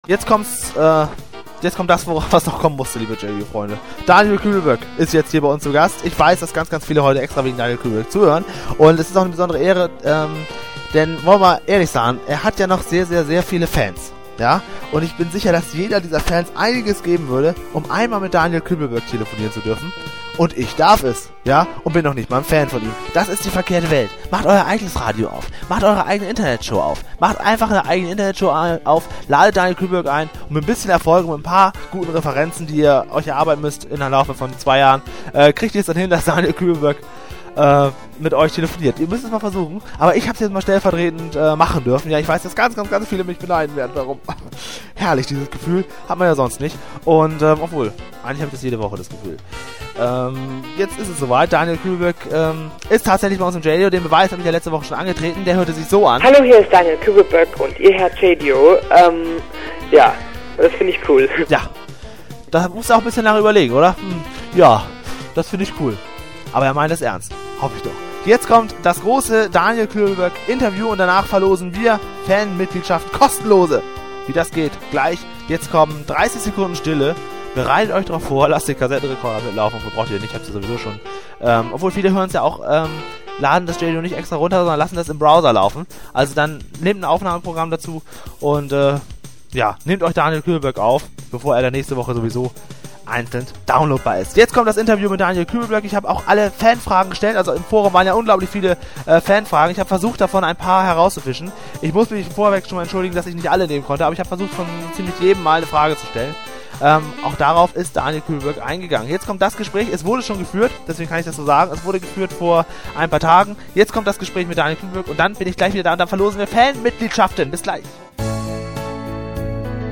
Jaydio ist eine wöchentliche Web-Show, in der es auch jedesmal ein Telefoninterview mit einem Künstler gibt. In der am 08.10. ausgestrahlten Sendung können sich die Zuhörer das einige Zeit zuvor aufgezeichnete Interview mit Daniel anhören.